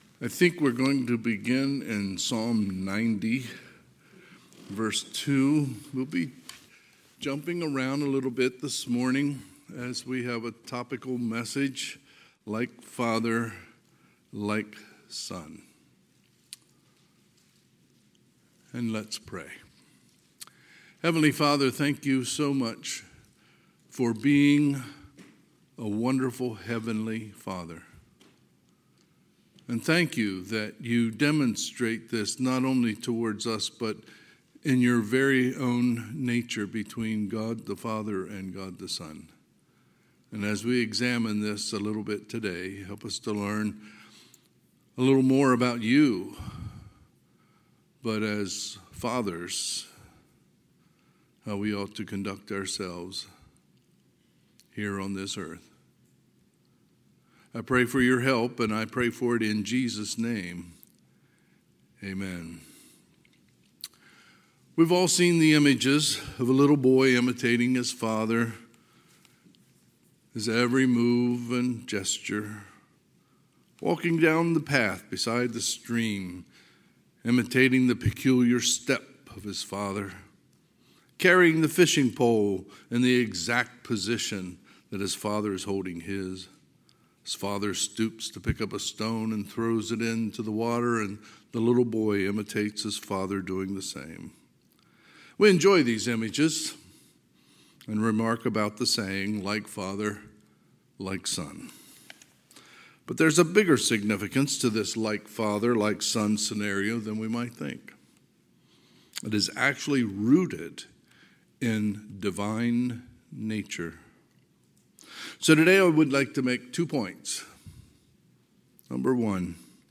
Sunday, June 18, 2023 – Sunday AM
Sermons